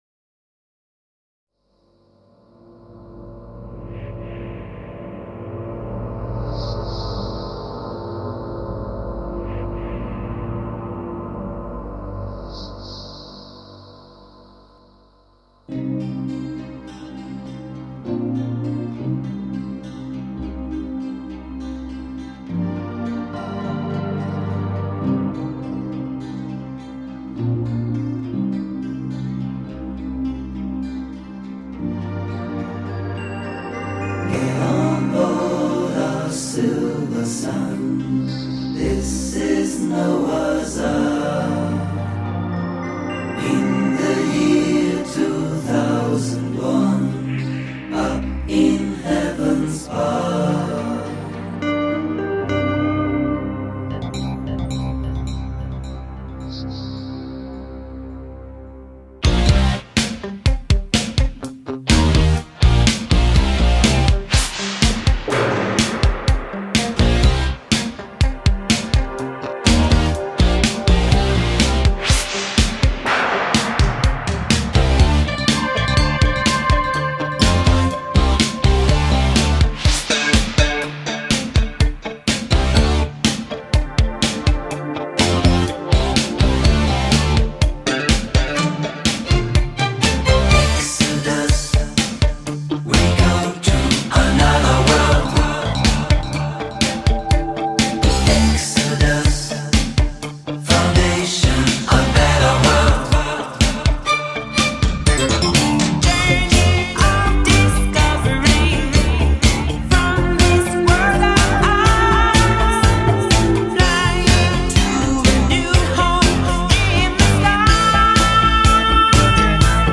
专辑风格：迪斯科